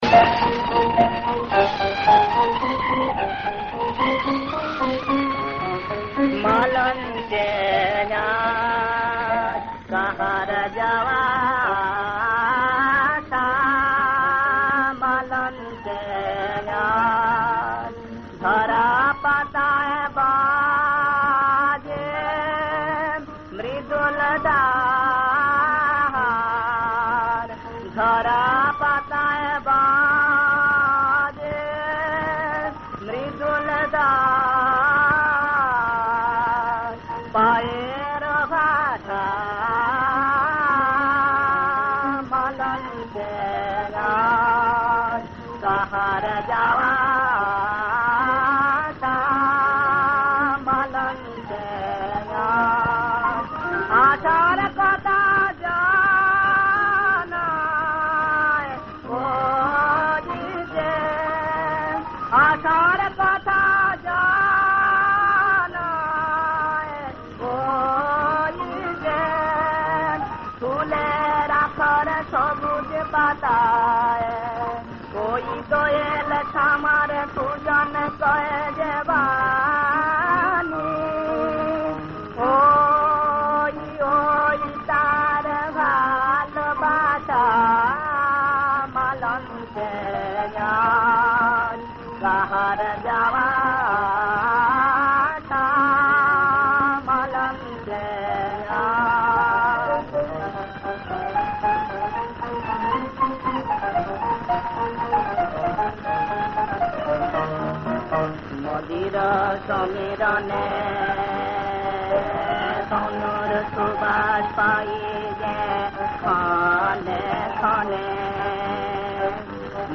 রাগ: খাম্বাজ, তাল: দাদরা।
• সুরাঙ্গ: রাগাশ্রয়ী